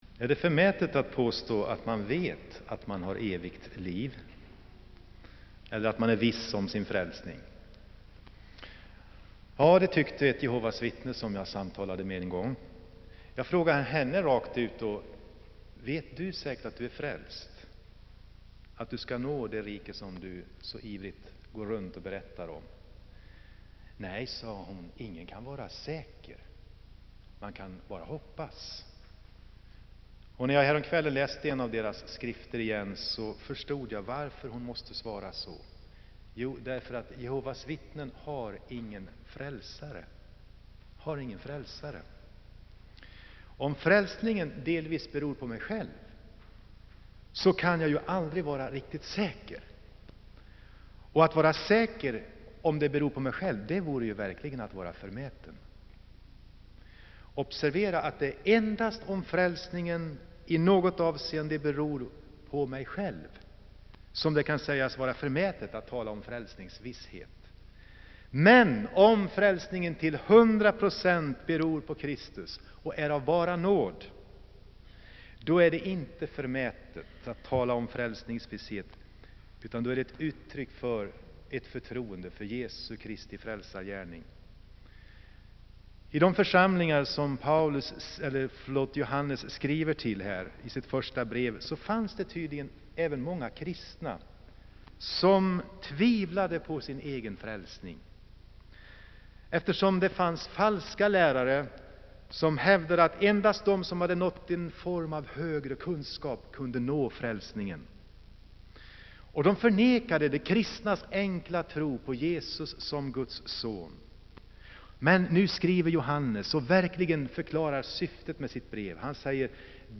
Inspelad i Saronkyrkan, Göteborg.